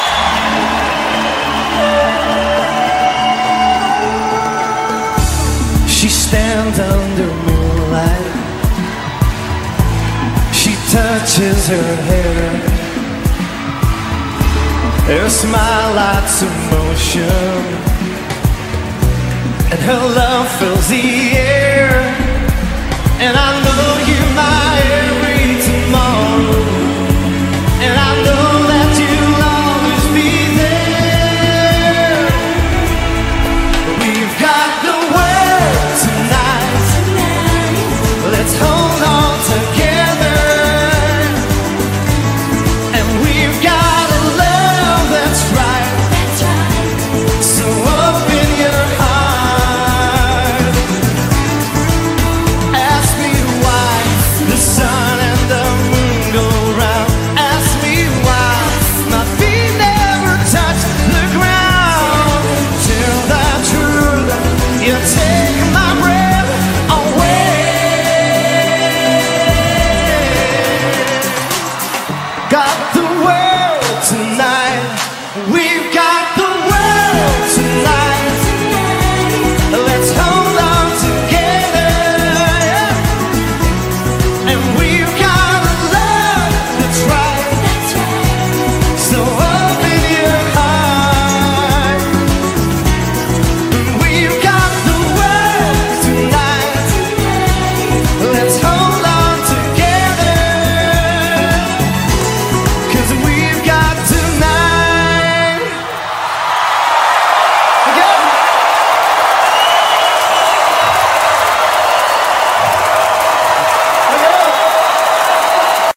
BPM104
Audio QualityPerfect (High Quality)